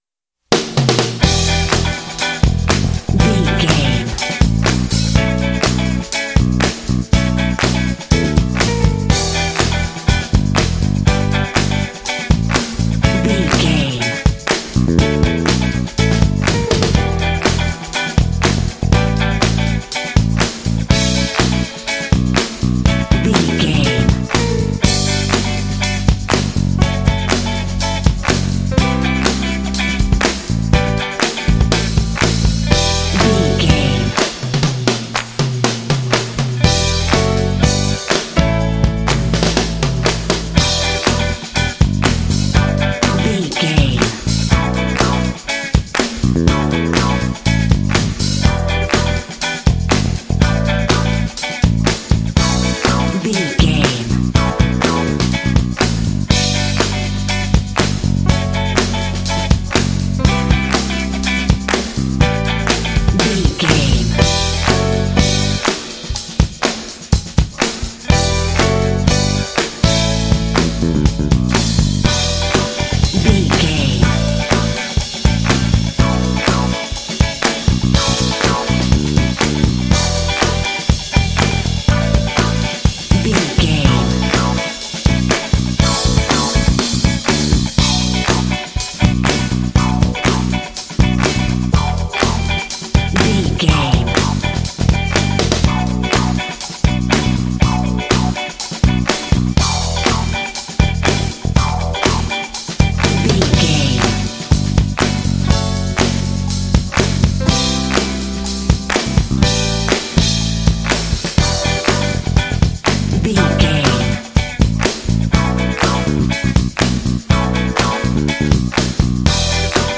Aeolian/Minor
funky
groovy
driving
energetic
lively
piano
bass guitar
electric guitar
drums